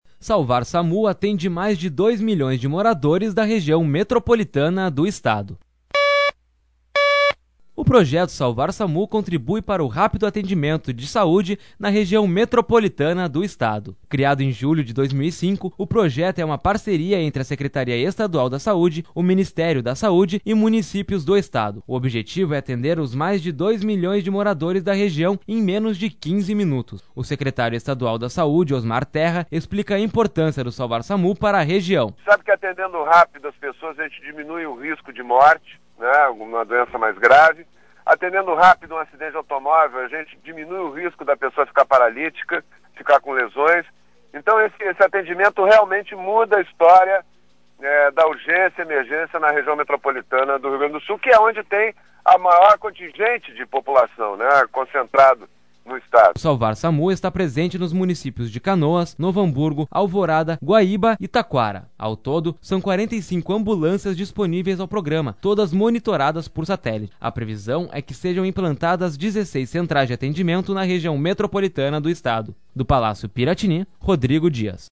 O projeto Salvar-SAMU contribui para o rápido atendimento de saúde na Região Metropolitana do Estado. Criado em julho de 2005, o projeto é uma parceria entre a Secretaria Estadual da Saúde, o Ministério da Saúde, e municípios. Sonora: secretário Estadu